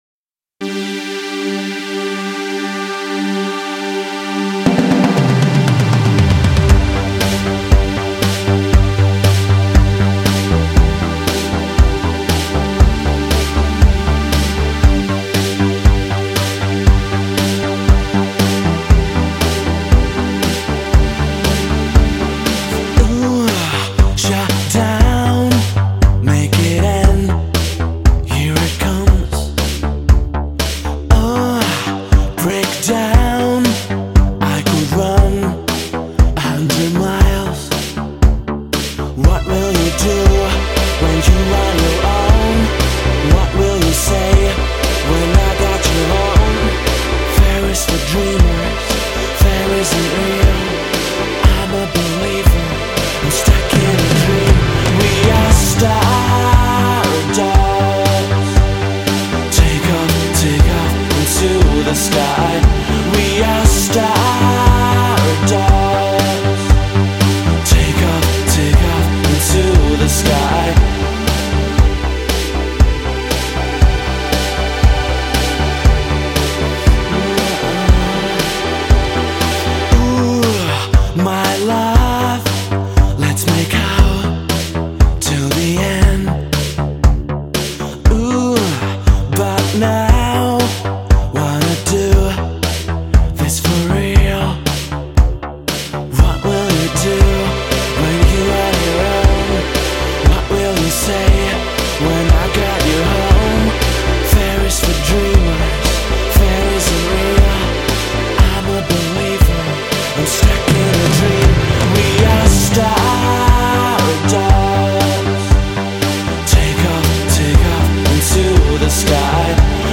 Žánr: Pop
Chytlavé melodie, snové synth plochy a výrazná rytmika